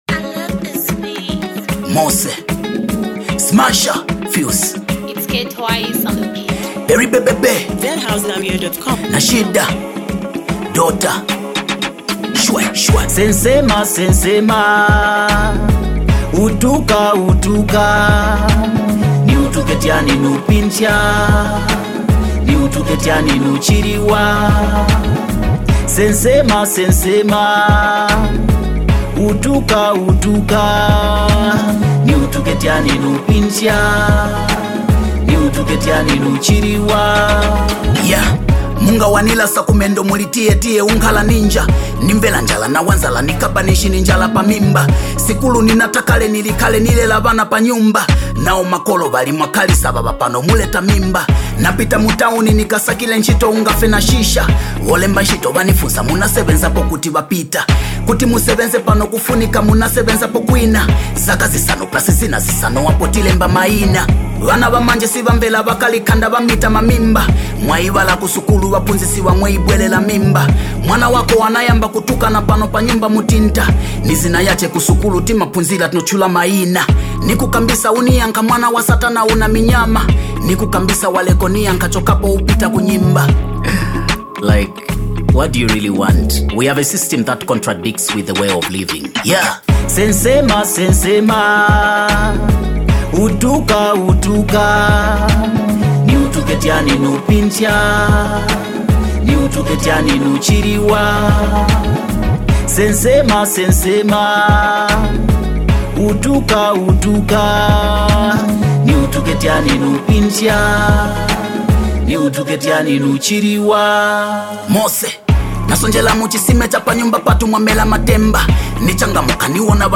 hip hop jam